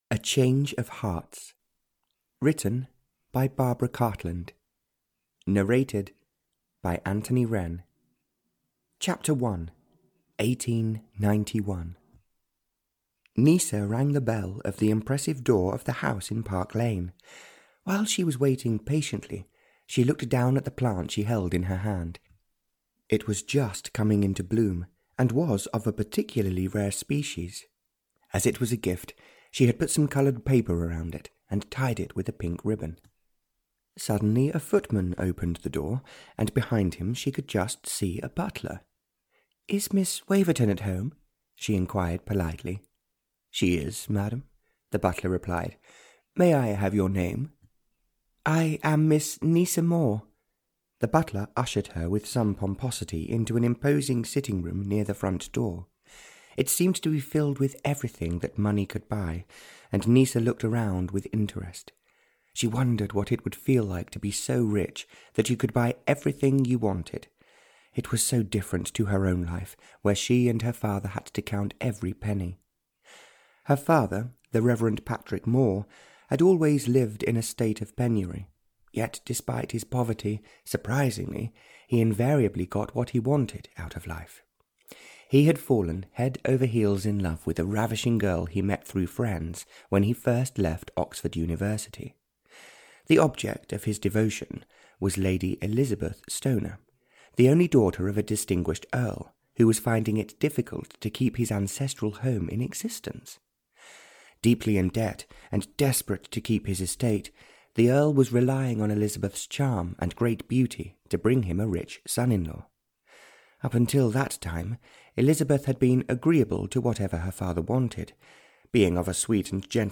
A Change of Hearts (Barbara Cartland’s Pink Collection 61) (EN) audiokniha
Ukázka z knihy